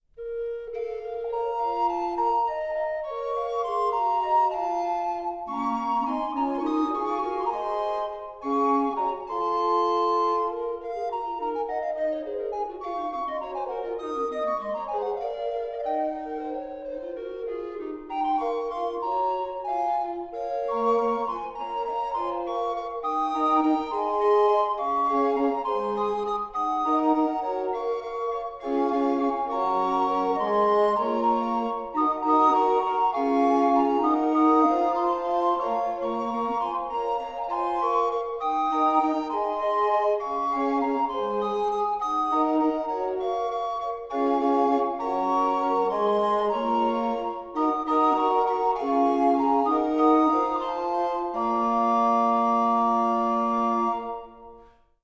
vihuela, guitar, shawm, recorder, percussion
sackbut, recorder
shawm, dulcian, recorders, bagpipes
shawm, harp, recorder, bagpipe
sackbut, recorders, bagpipes, percussion